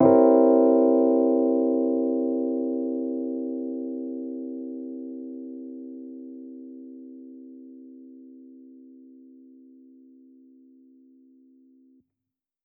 Index of /musicradar/jazz-keys-samples/Chord Hits/Electric Piano 2
JK_ElPiano2_Chord-C7b9.wav